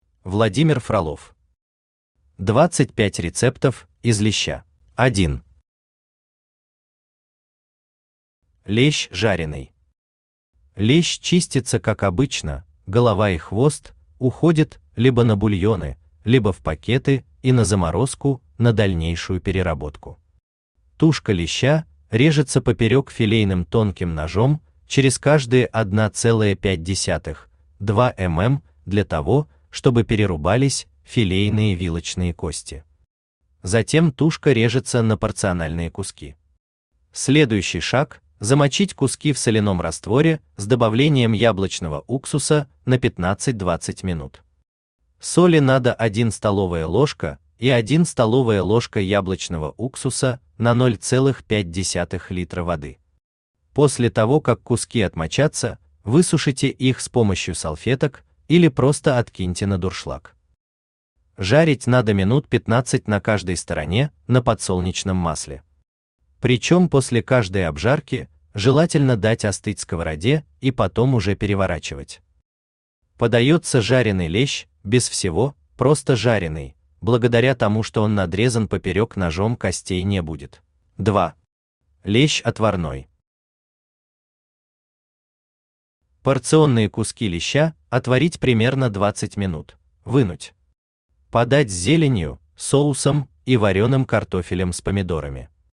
Аудиокнига 25 рецептов из леща | Библиотека аудиокниг
Aудиокнига 25 рецептов из леща Автор Владимир Владимирович Фролов Читает аудиокнигу Авточтец ЛитРес.